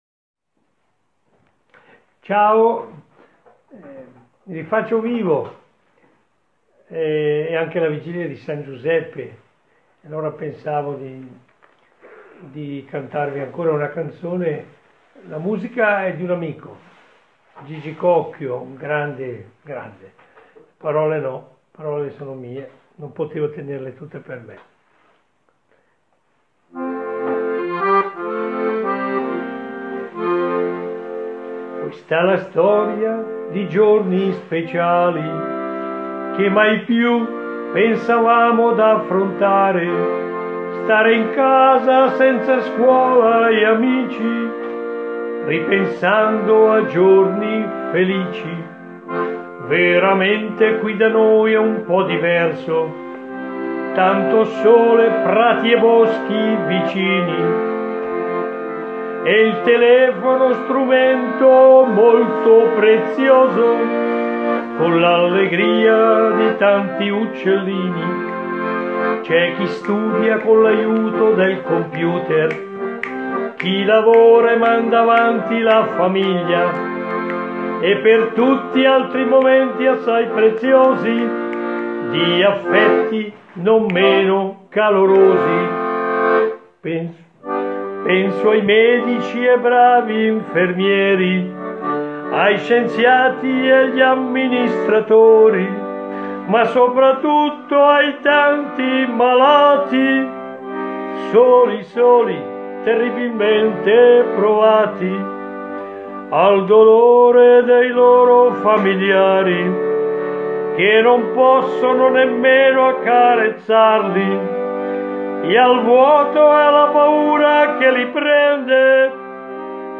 Radio - Una canzone-preghiera per questo strano giorno di San Giuseppe.
Un amico prete e fisarmonicista